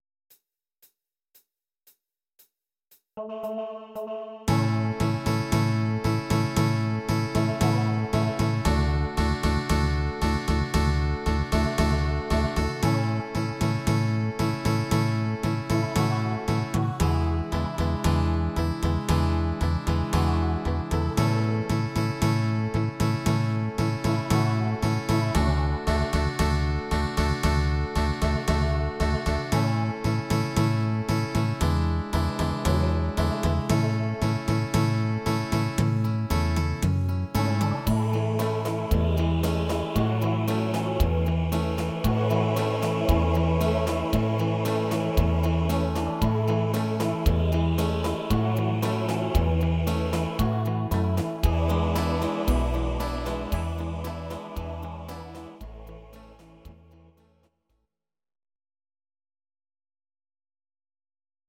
These are MP3 versions of our MIDI file catalogue.
Please note: no vocals and no karaoke included.
Your-Mix: Country (821)